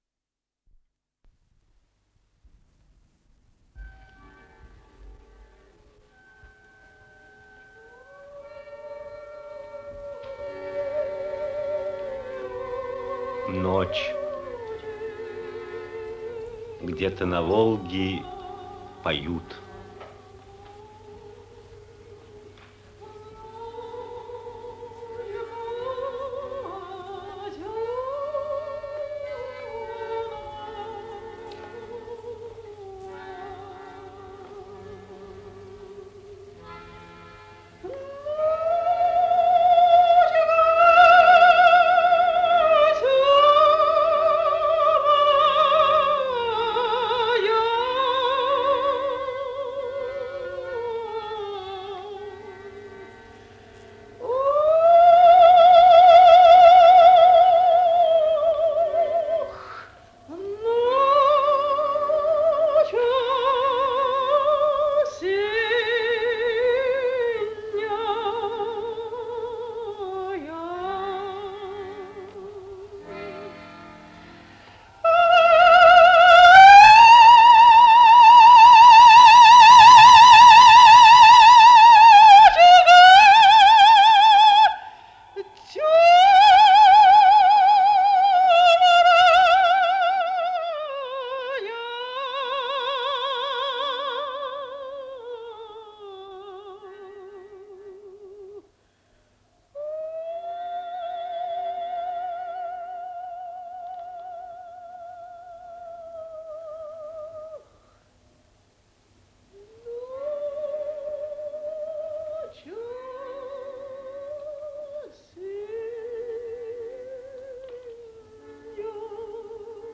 Исполнитель: Артисты театра им. Ленинского комсомола